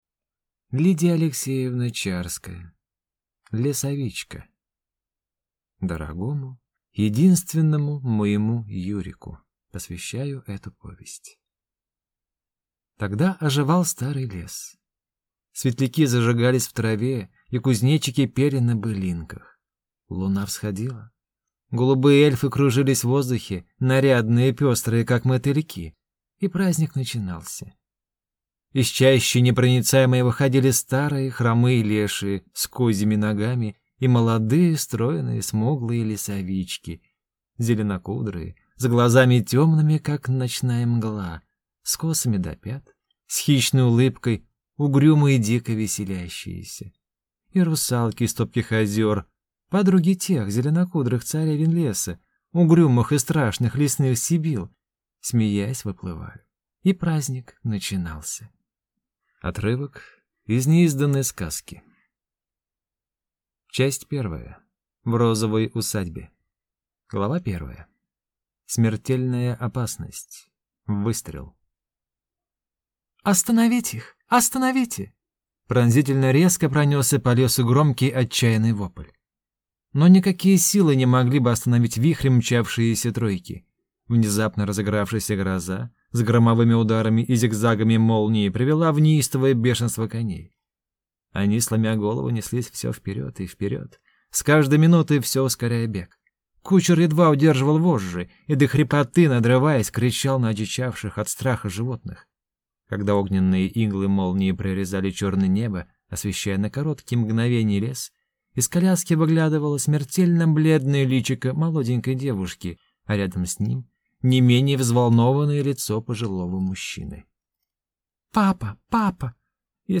Аудиокнига Лесовичка | Библиотека аудиокниг